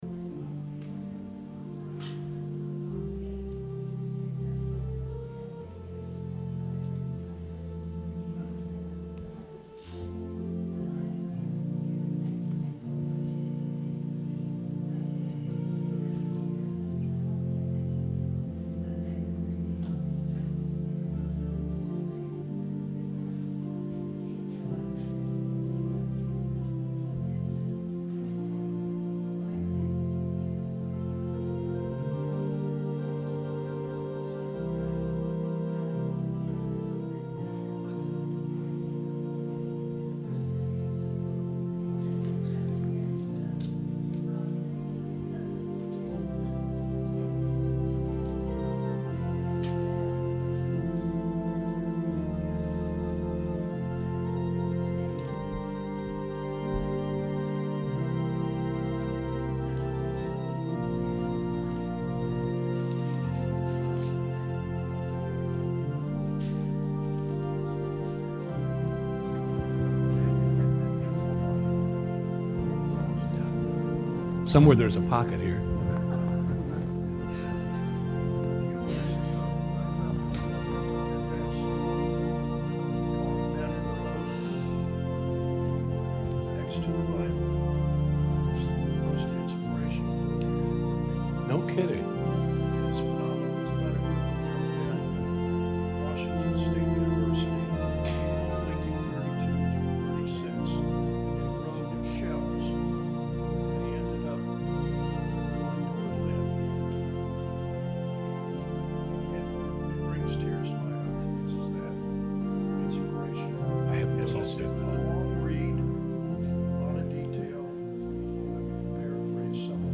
Sermon – February 10, 2019 – Advent Episcopal Church